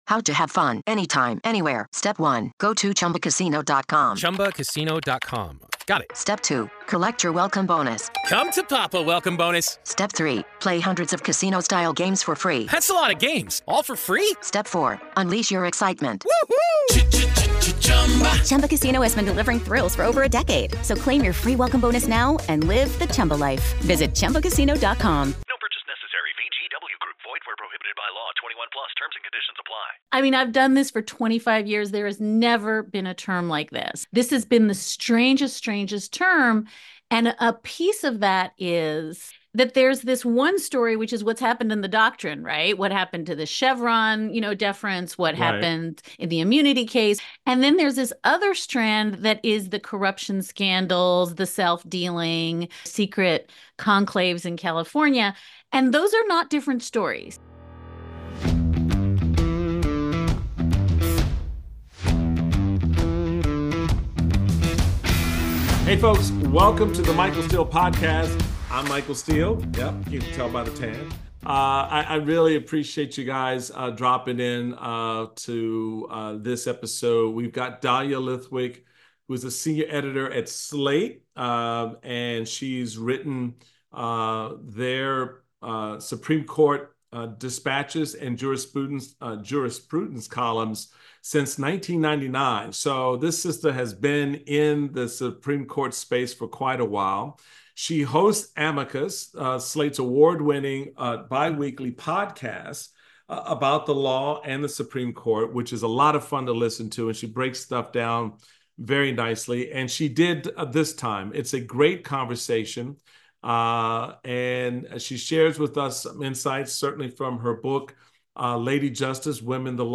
Michael Steele speaks with Dahlia Lithwick, Senior Editor at Slate and host of Slate's Supreme Court podcast, Amicus. The pair discuss the current state of the Supreme Court and the need for reform, regulation and expansion.